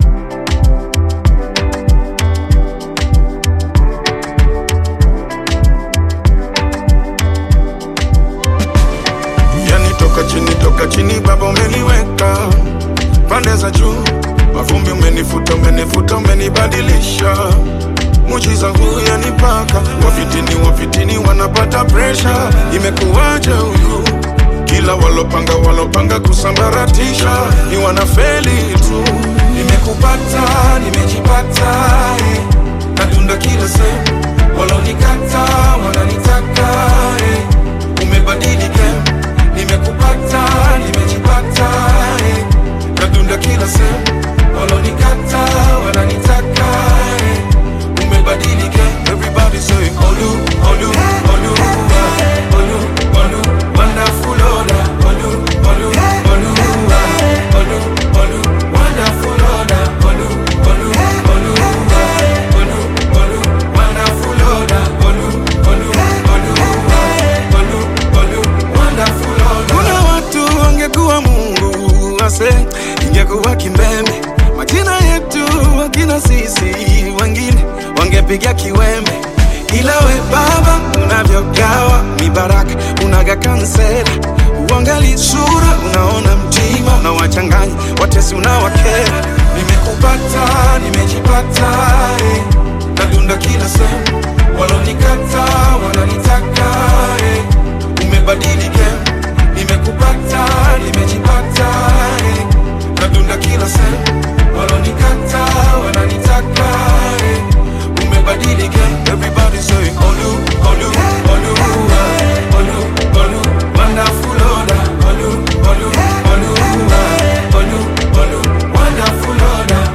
Gospel music track